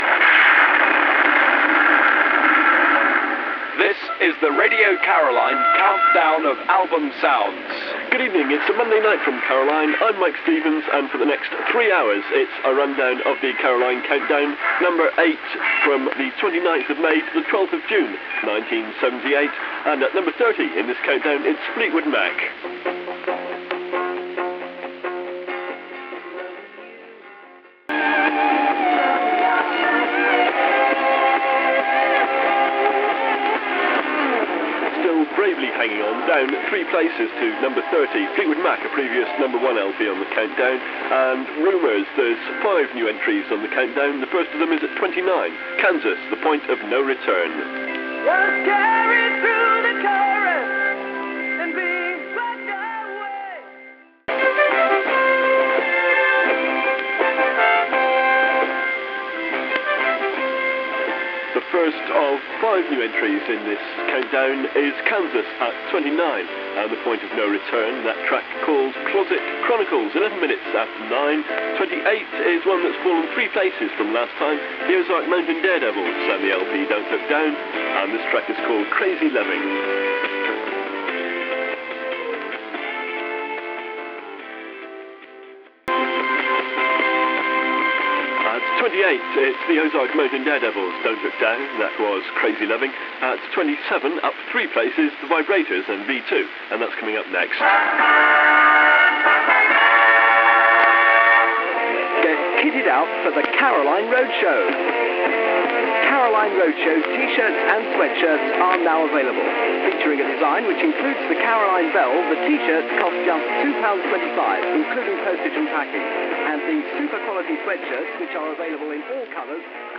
kicks off the chart show